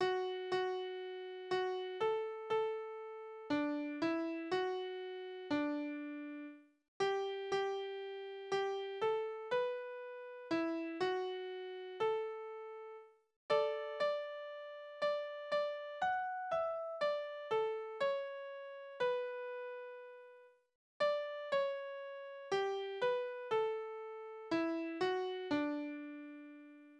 Naturlieder
Tonart: D-Dur
Tonumfang: große Dezime
Besetzung: vokal
Anmerkung: Taktart ist nicht eindeutig bestimmbar, es müsste sich jedoch in weiten Teilen um einen geraden Takt handeln Textverteilung fragwürdig